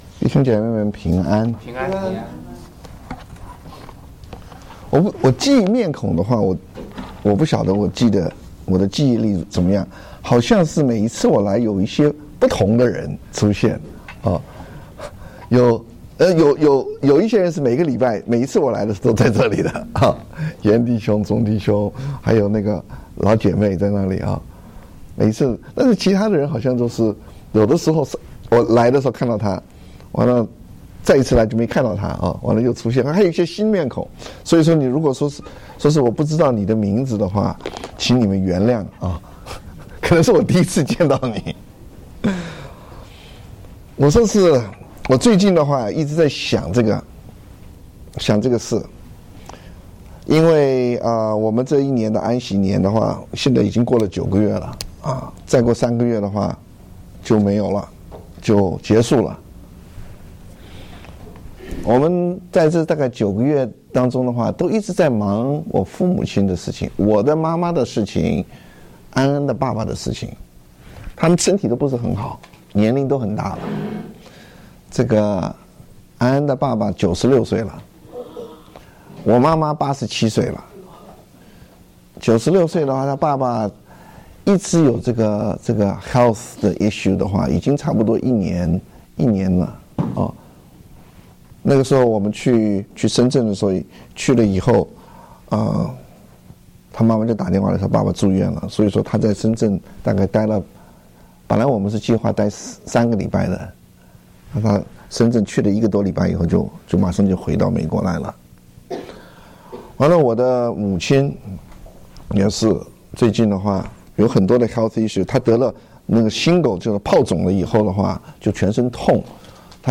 證道